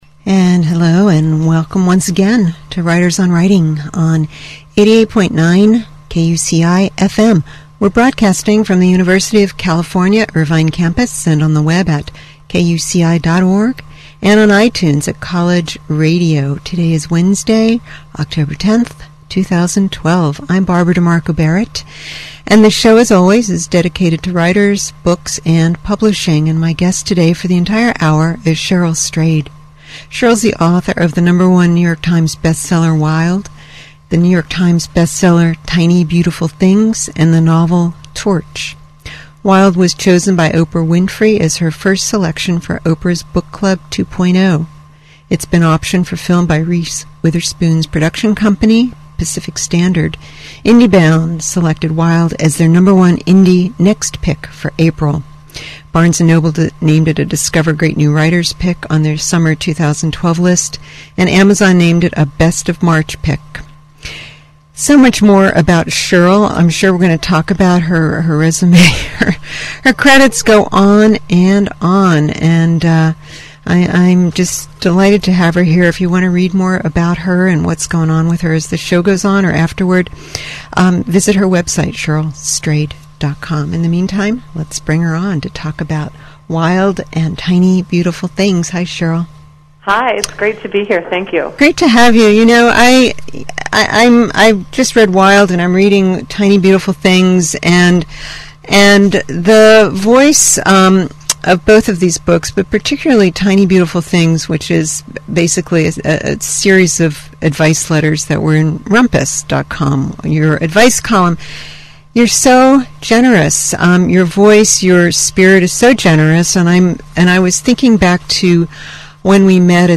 Dublin-based novelist (journalist, screenwriter, book reviewer) John Banville for an hour to about his latest novel, Ancient Light, his mystery series, writing, writers, and more, and as the show goes on, it gets downright jolly.